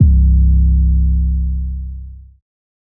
808_Oneshot_Heat_C.wav